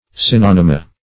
synonyma - definition of synonyma - synonyms, pronunciation, spelling from Free Dictionary
Search Result for " synonyma" : The Collaborative International Dictionary of English v.0.48: Synonyma \Syn*on"y*ma\ (s[i^]n*[o^]n"[i^]*m[.a]), n. pl.